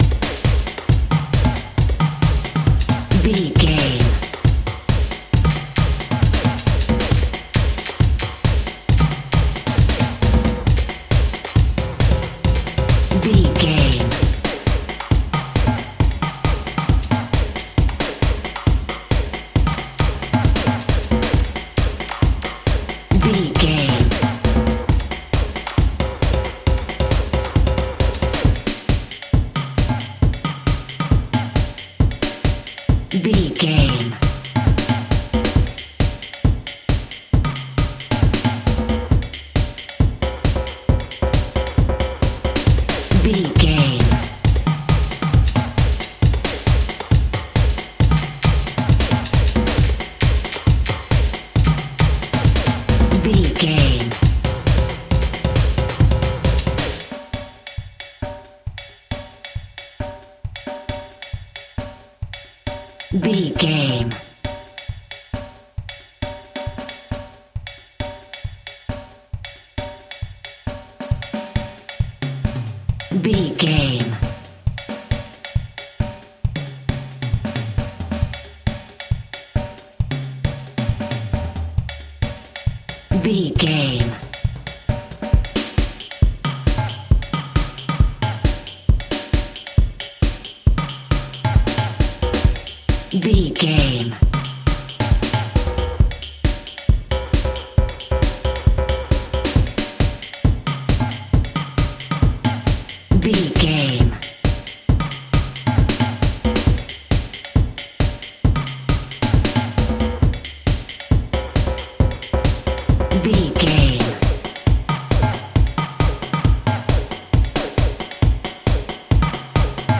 Fast paced
Atonal
Fast
ethnic percussion